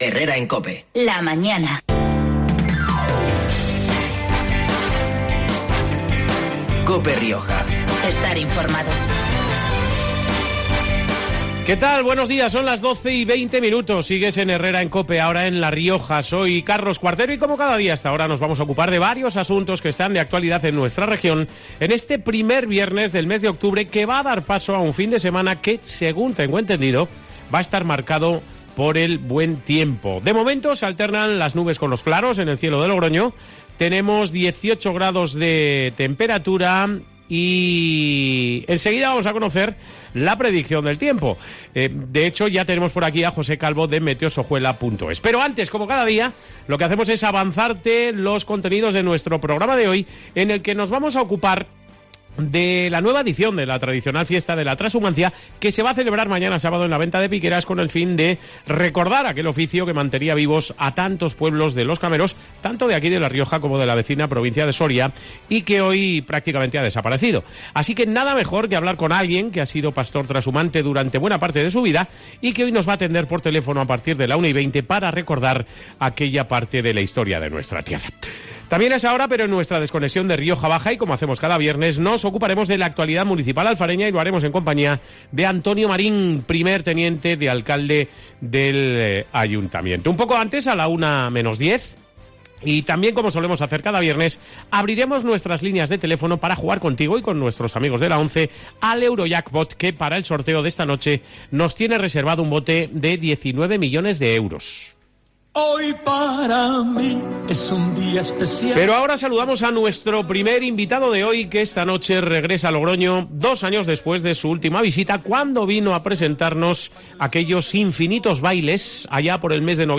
Hoy hablamos con el artista, que nos cuenta cómo está funcionando esta última gira que le está llevando por toda España para presentar su último disco grabado en los prestigiosos estudios 'Abbey Road' de Londres.